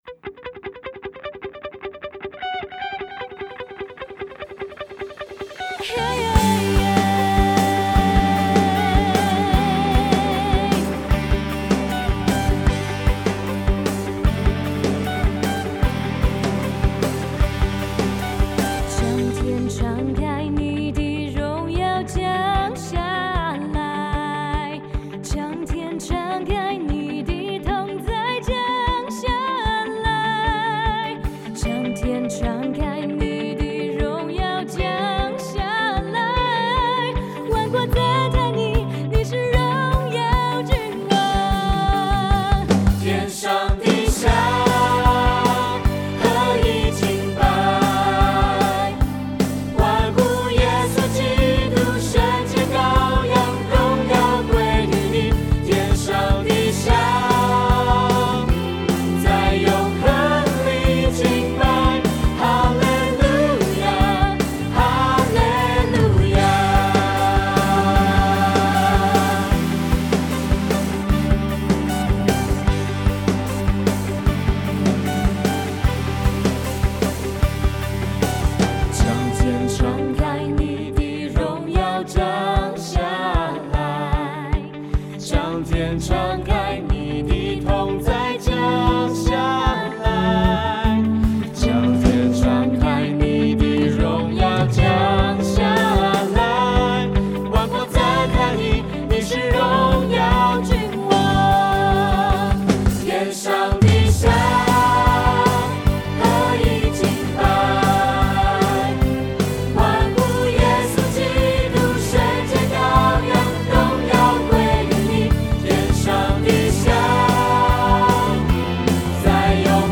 第3336首-将天敞开（G调）.mp3